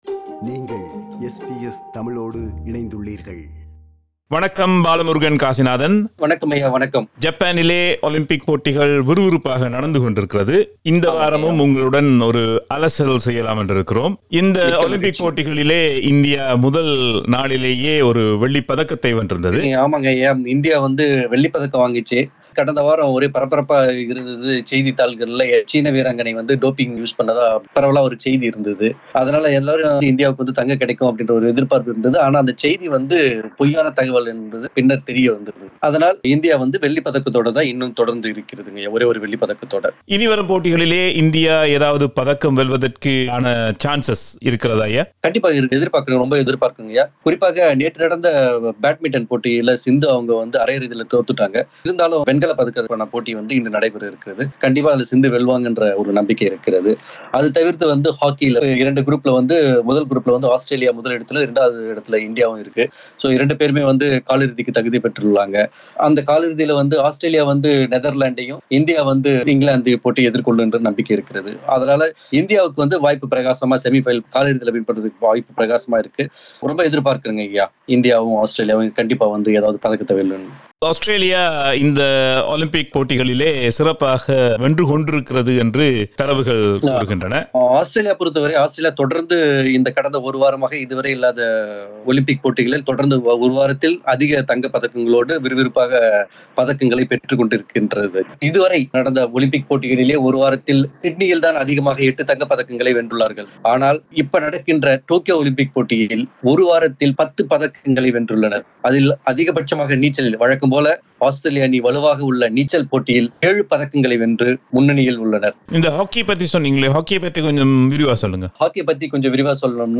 ஜப்பானில் நடக்கும் ஒலிம்பிக் போட்டிகள் குறித்த ஒரு விவரணம்...